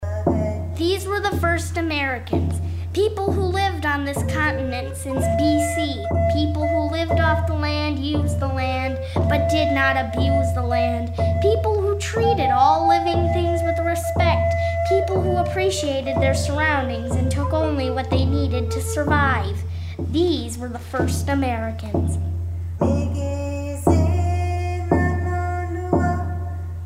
fun and quirky songs